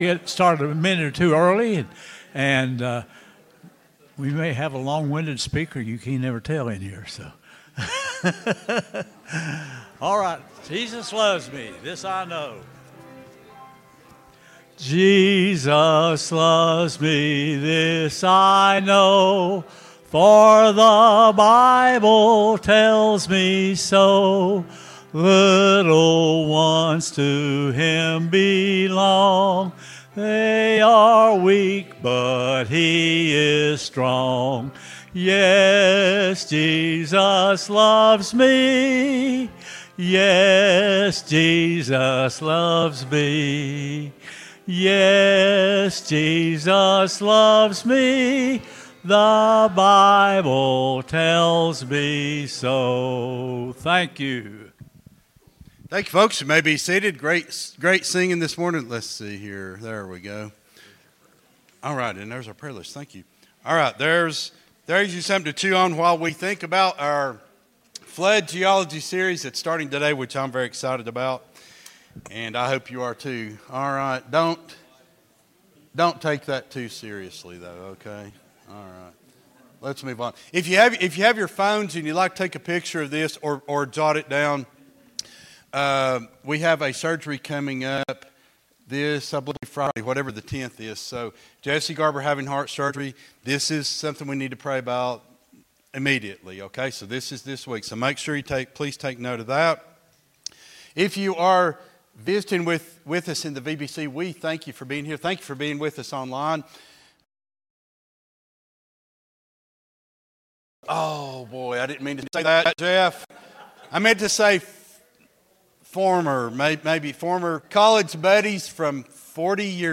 10-05-25 Sunday School Lesson | Buffalo Ridge Baptist Church